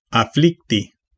Ääntäminen
IPA: [a.tɛ̃dʁ]